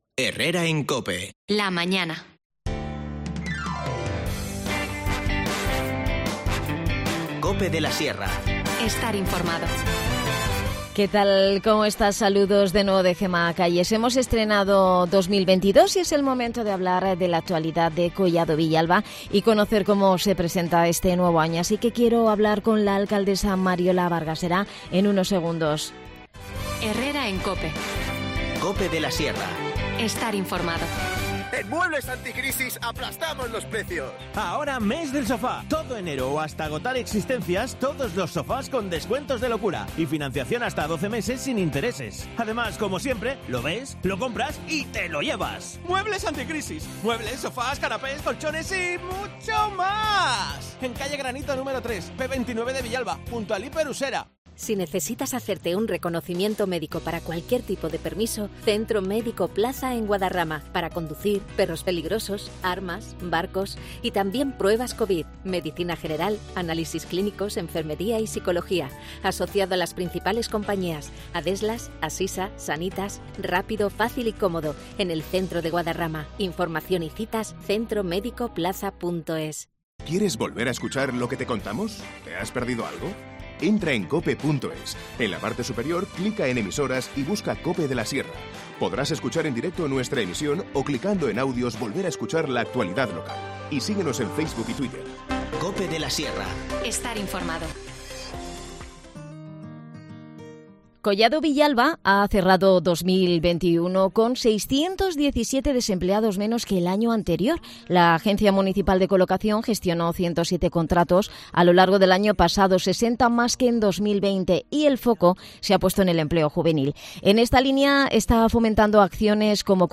Charlamos con Mariola Vargas, alcadesa de Collado Villalba, sobre la inversión que hará el Ayuntamientode cara a este nuevo año en mejoras en la localidad. El presupuesto asciende a más de 10 millones de euros.